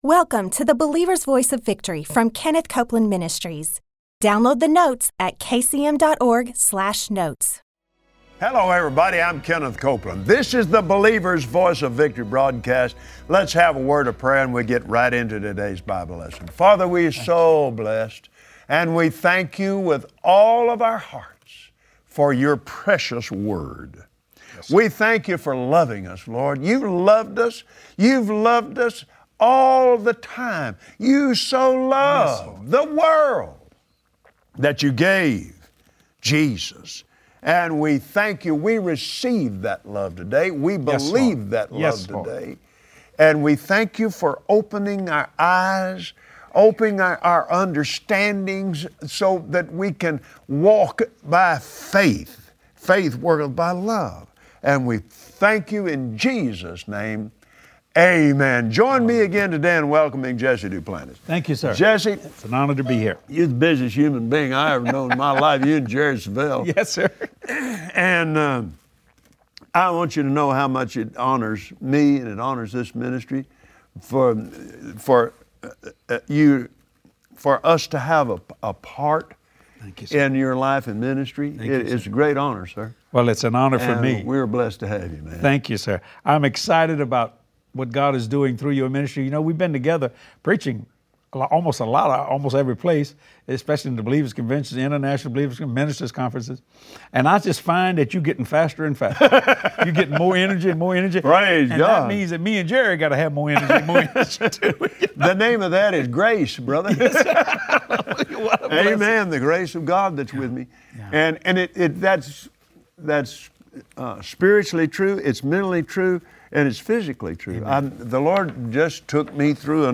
Today, Kenneth Copeland and Jesse Duplantis bring you words of encouragement to search the scripture daily and be ready to follow God’s plan for your life.